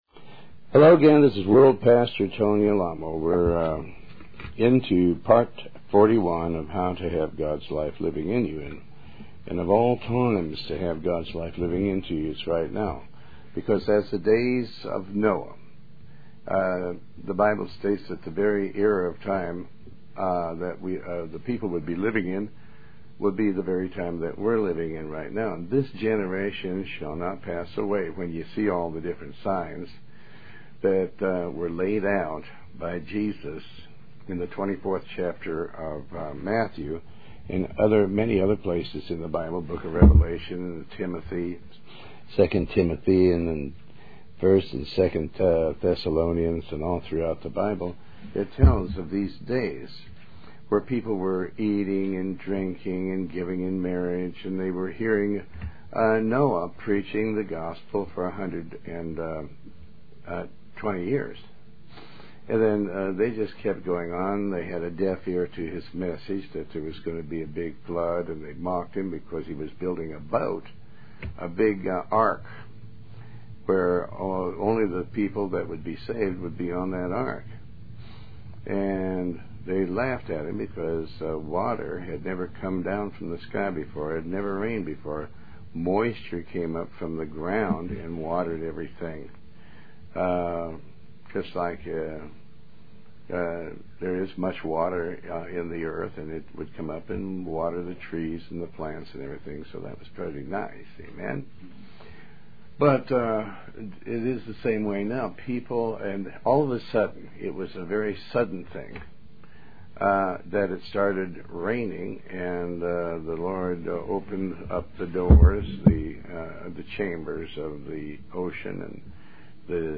Talk Show
Show Host Pastor Tony Alamo